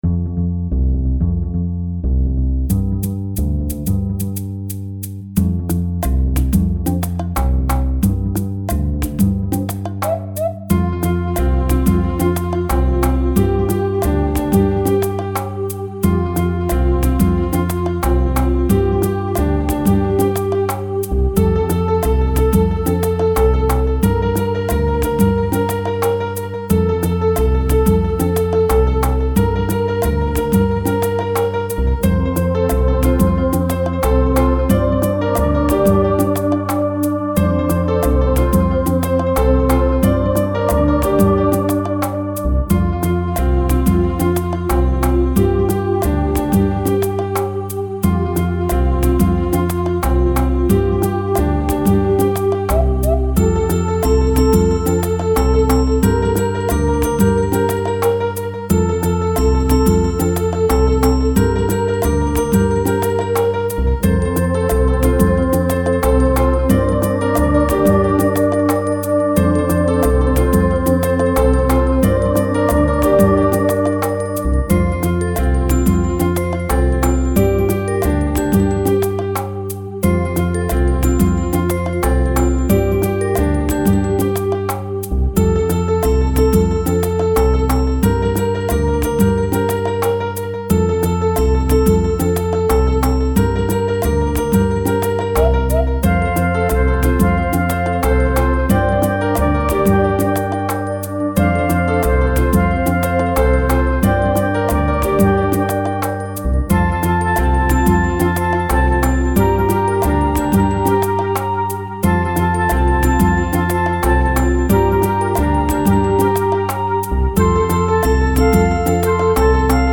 Children’s song, Europe
karaoke
instrumental, 2:44 – 4/4 – 90 bpm